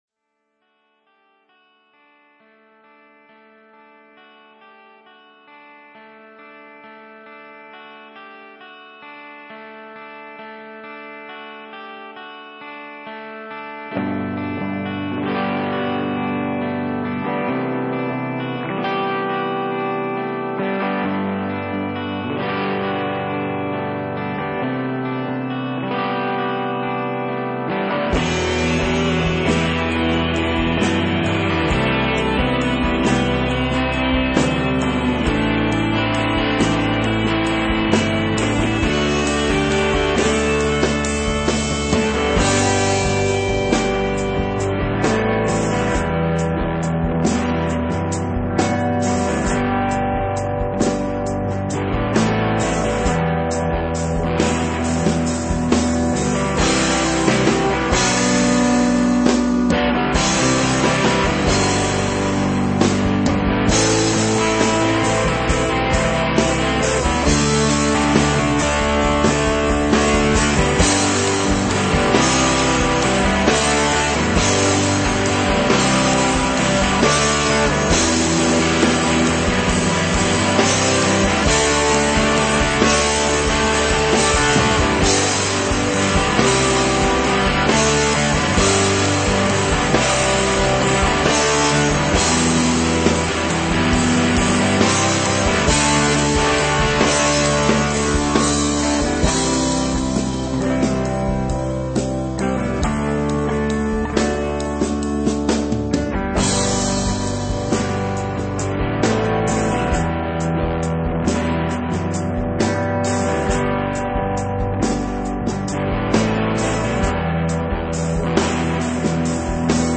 hard rock
metal
punk
high energy rock and roll